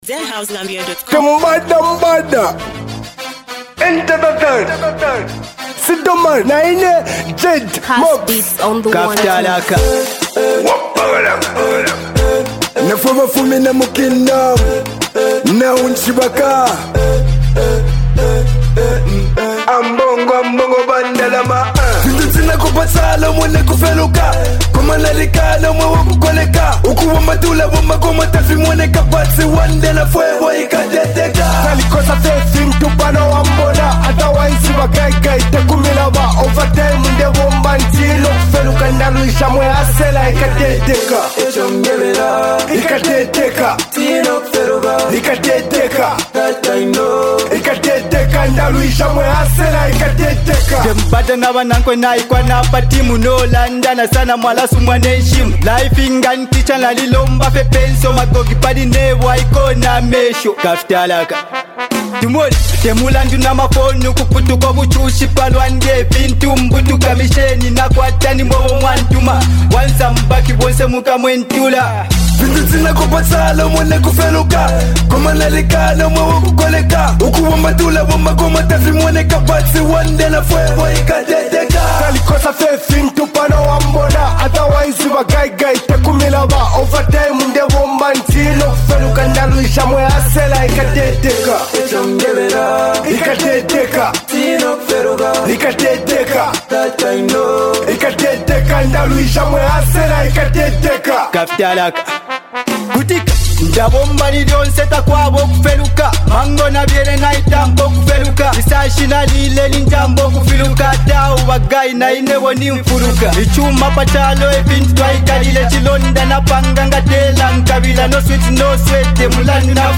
a smooth dancehall vibe